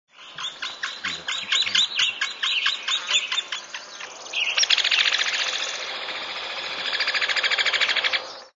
Click to hear the downy woodpecker singing (40K MP3)
THE DOWNY WOODPECKER
downy.mp3